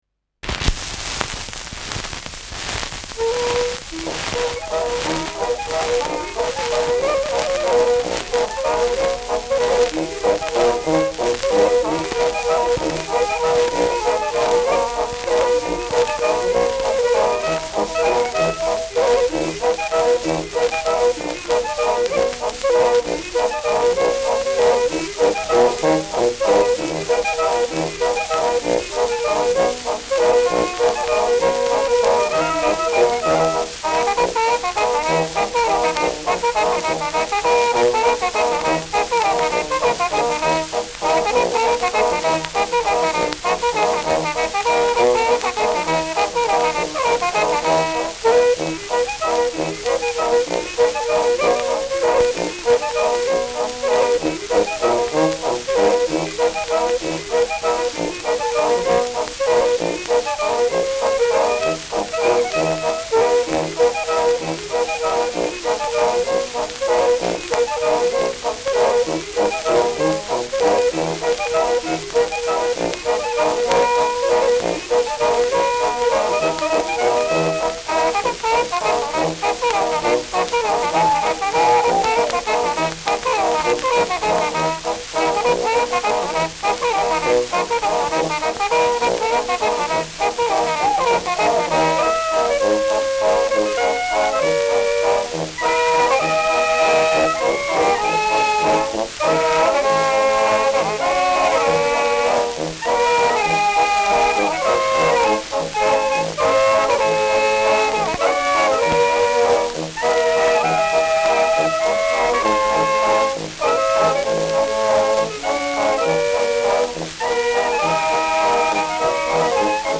Schellackplatte
präsentes Rauschen : präsentes Knistern : leiert : abgespielt : Knacken : deutliches Nadelgeräusch
Kapelle Dorn, Happurg (Interpretation)
Juchzer, Ausruf am Ende